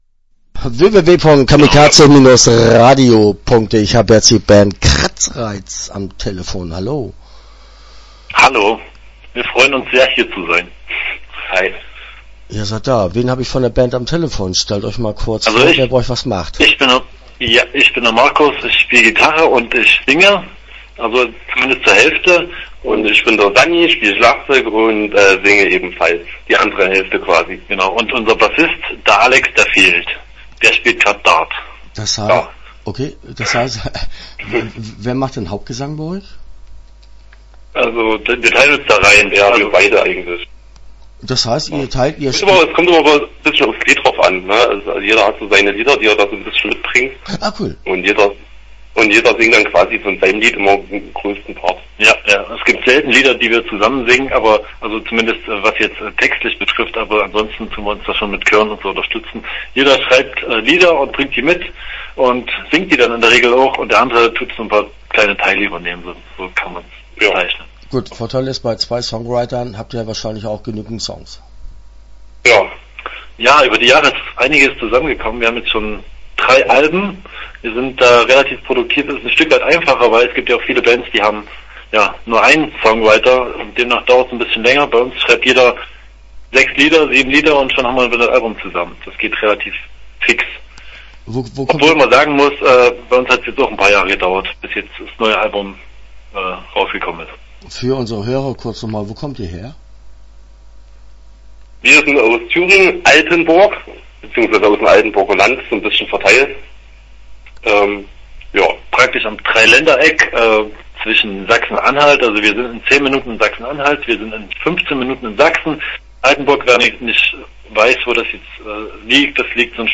Kratzreiz - Interview Teil 1 (10:41)